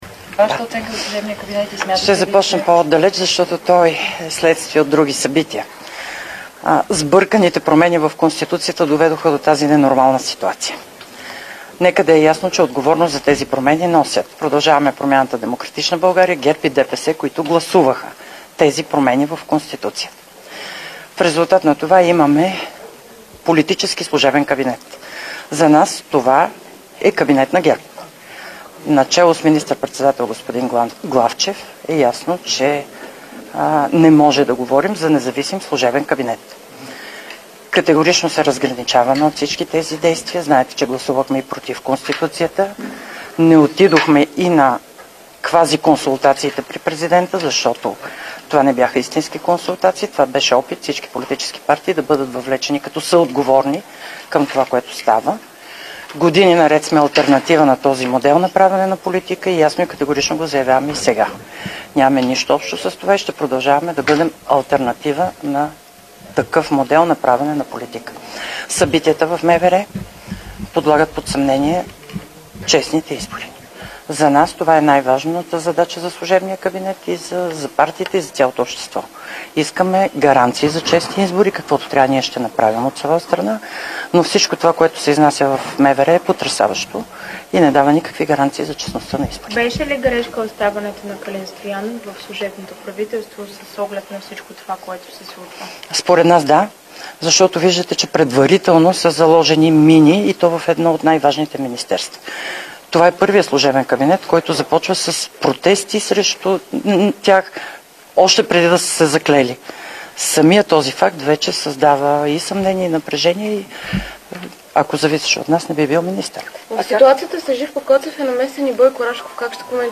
Директно от мястото на събитието